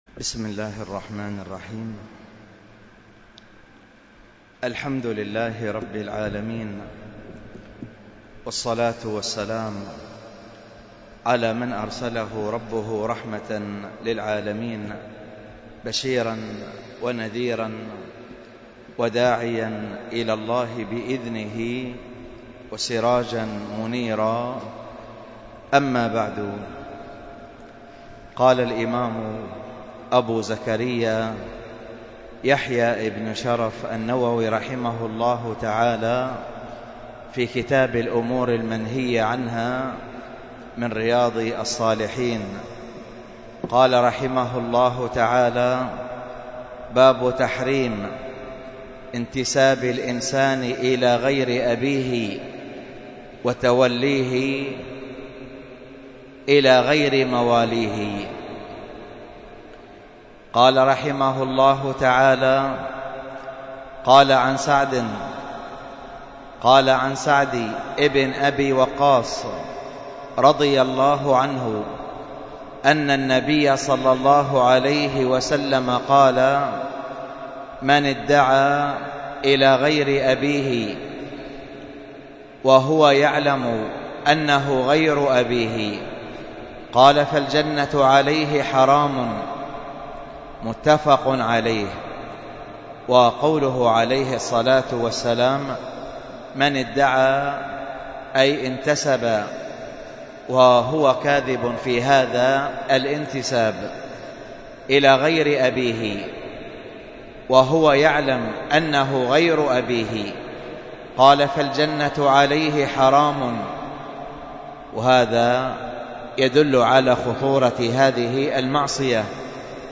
المحاضرة بعنوان من صفات الأسرة المسلمة، وكانت بمسجد الخير بالخزان - الشحر